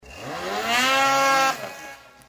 An after-market or altered snowmobile exhaust system is loud.
Here’s a genuine recording of what an altered exhaust sounds like: